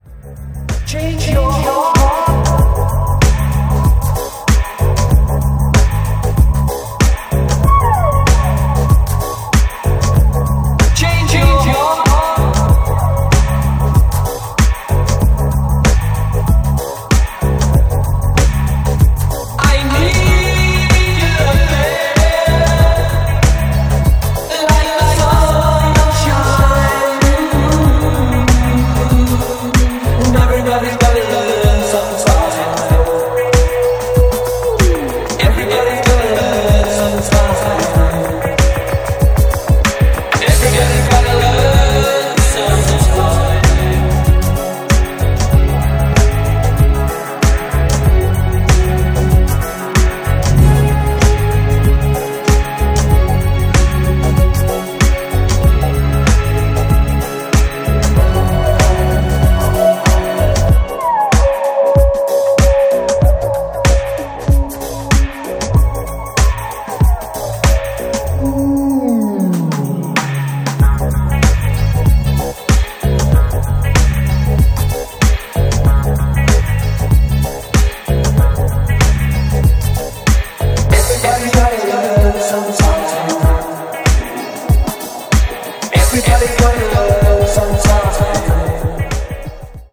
backed with the Balearic banger of the year